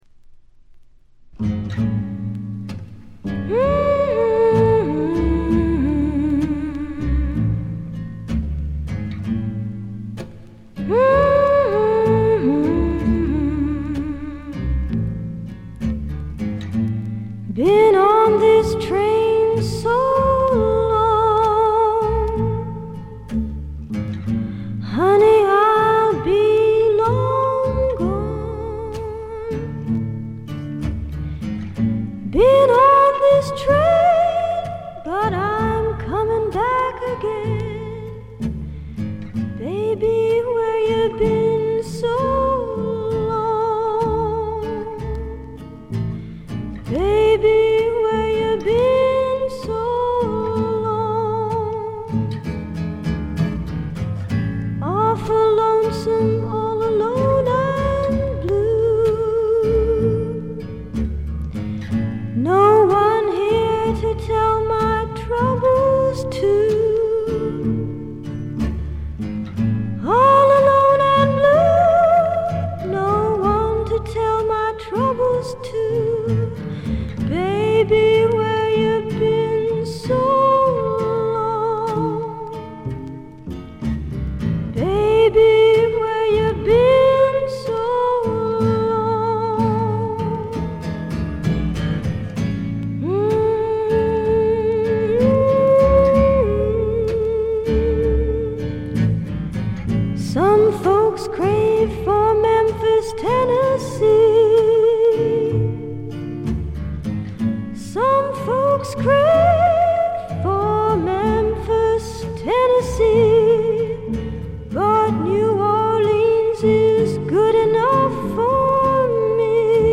軽微なバックグラウンドノイズやチリプチ。
美しくも素朴な味わいのあるソプラノ・ヴォイスに癒やされてください。
試聴曲は現品からの取り込み音源です。